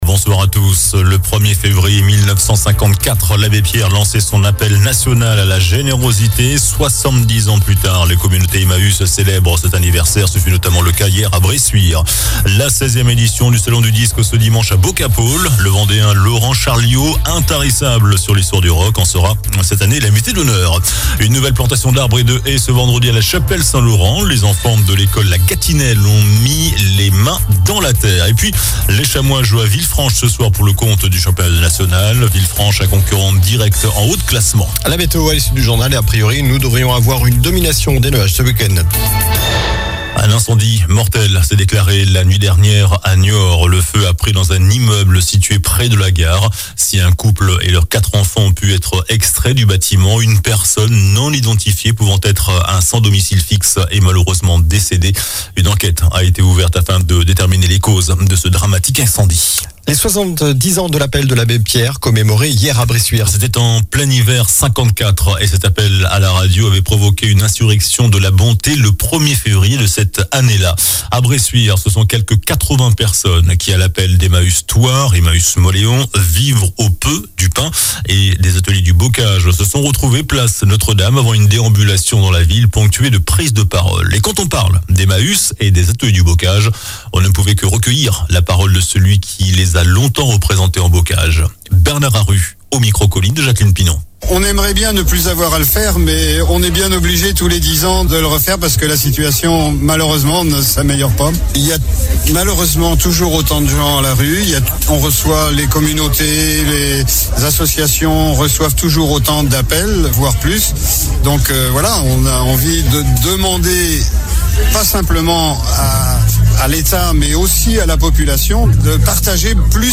JOURNAL DU VENDREDI 02 FEVRIER ( SOIR )